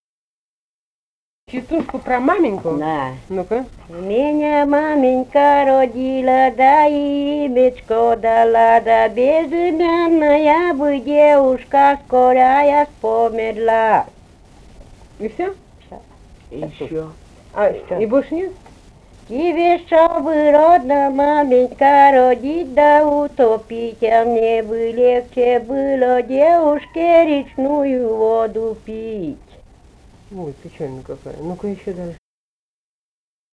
«Меня маменька родила» (частушки).